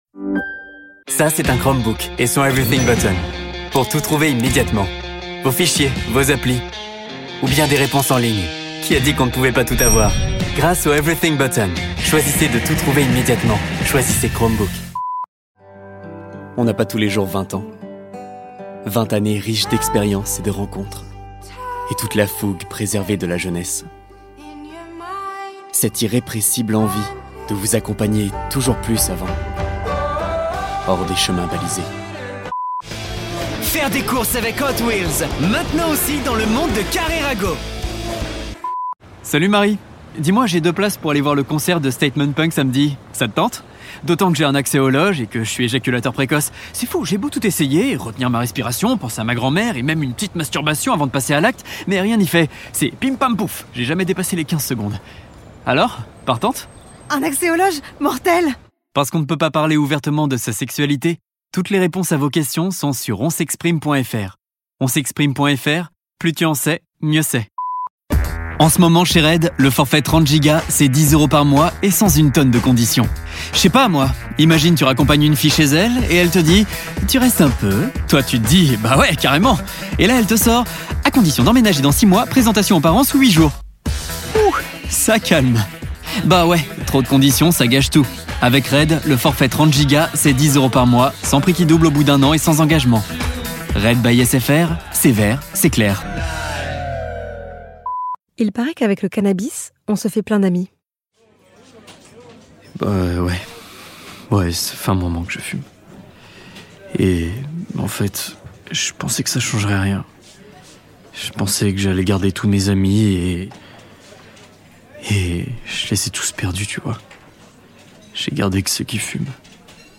Bande démo voix off